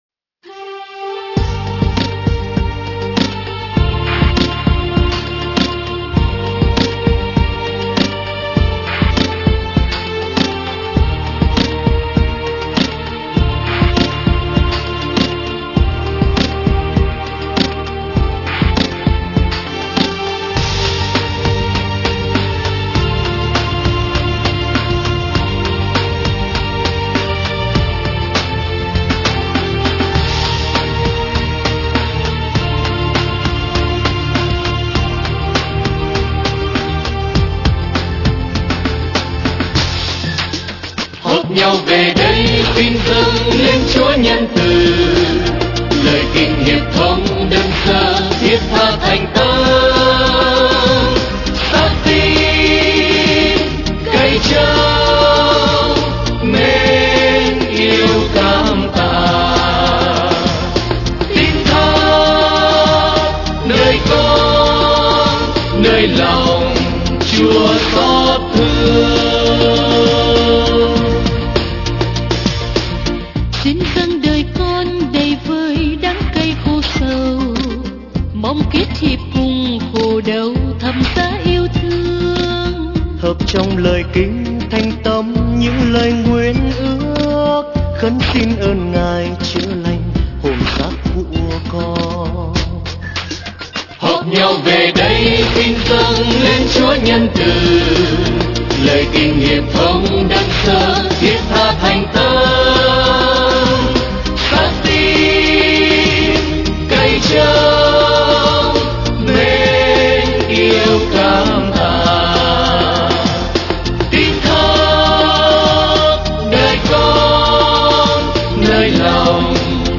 Dòng nhạc : Ngợi ca Thiên Chúa